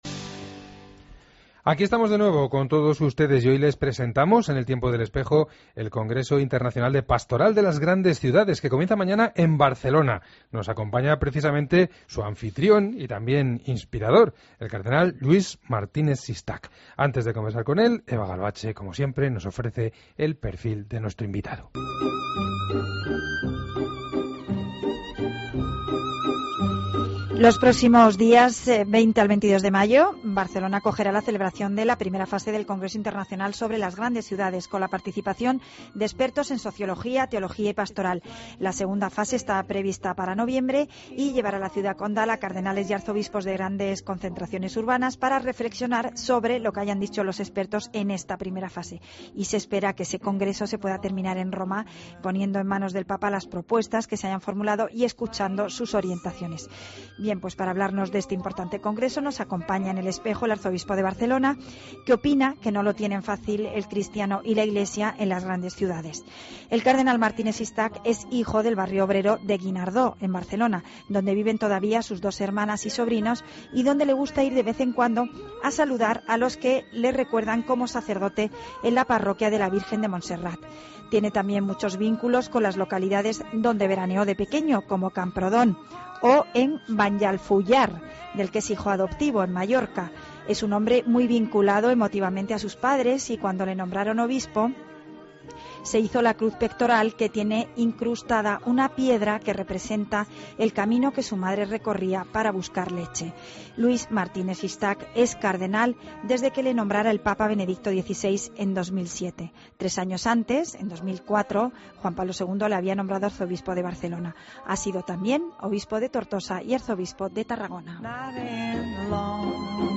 Escucha la entrevista completa a monseñor Lluis Martínez Sistach en 'El Espejo'